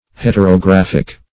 Search Result for " heterographic" : The Collaborative International Dictionary of English v.0.48: Heterographic \Het`er*o*graph"ic\, a. [See Heterography .]
heterographic.mp3